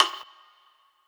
Closed Hats
WOODBVLOCK.wav